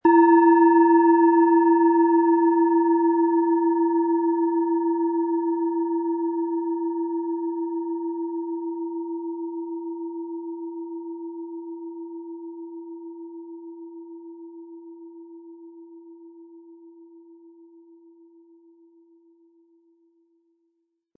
Hopi Herzton
Im Preis enthalten ist ein passender Klöppel, der die Töne der Schale schön zum Schwingen bringt.
SchalenformBihar
MaterialBronze